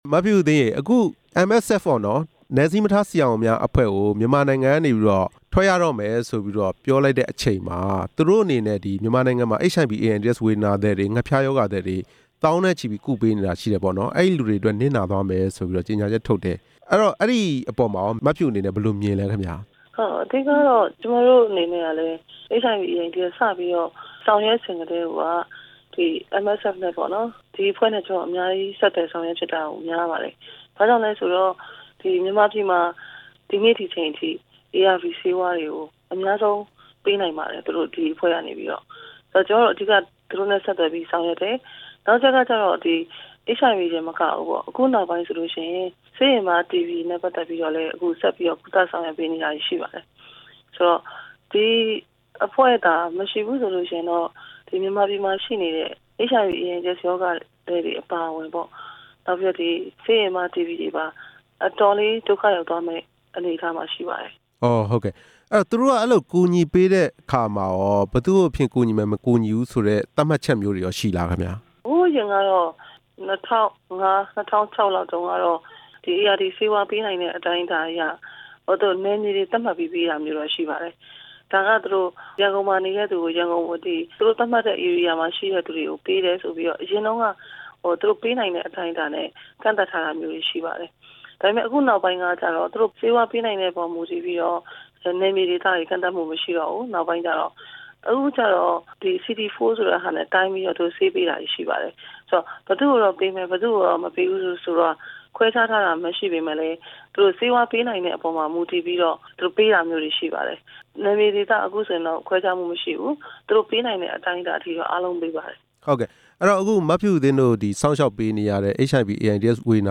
ဒေါ်ဖြူဖြူသင်းနဲ့ မေးမြန်းချက်